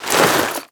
tac_gear_5.ogg